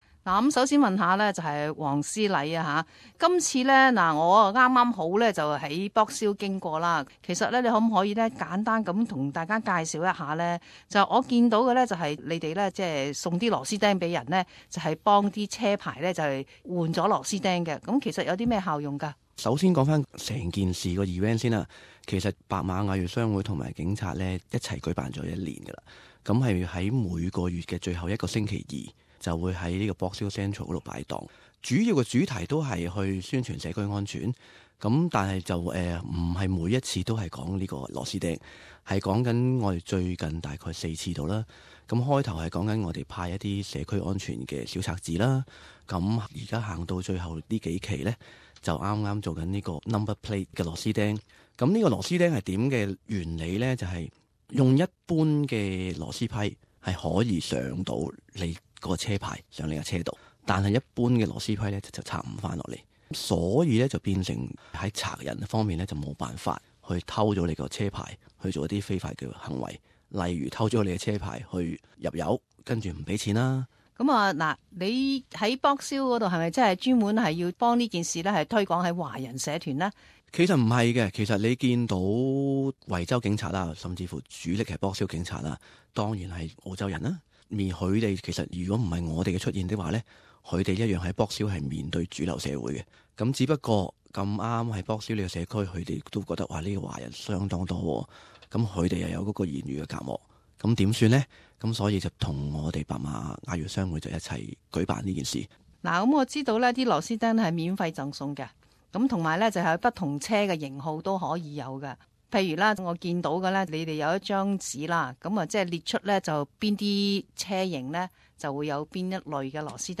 【社团访问】维州警察与华人社团共创社区安全环境